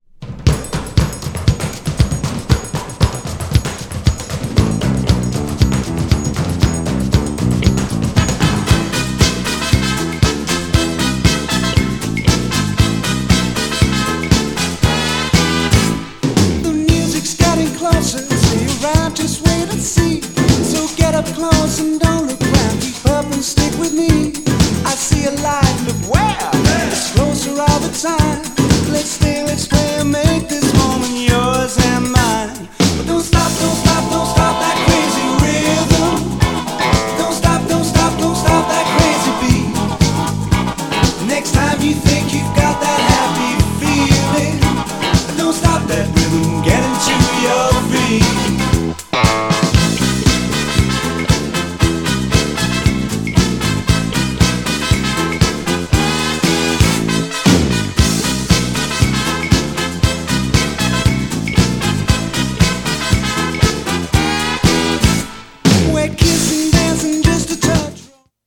BIG BAND風の
SWING JAZZなムードのA面に、センチメンタルなシンセPOPサウンドのB面、どちらも良い!!
GENRE Dance Classic
BPM 111〜115BPM